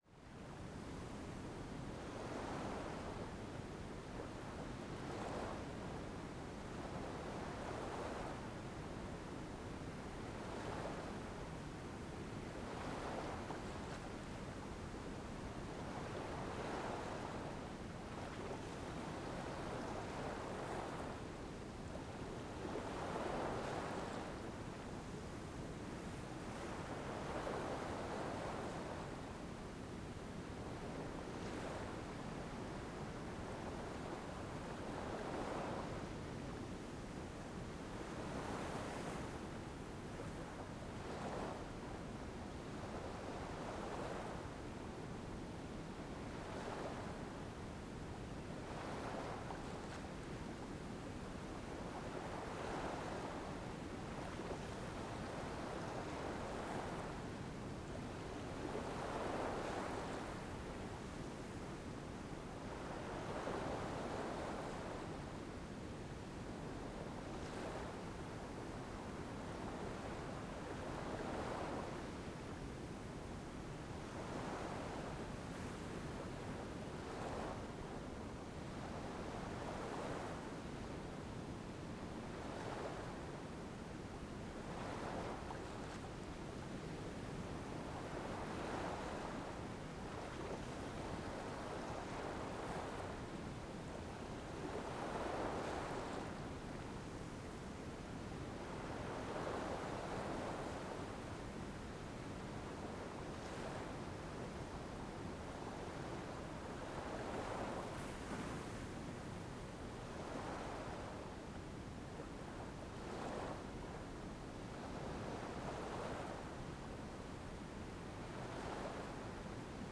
09 - Sons de mer pour dormir.flac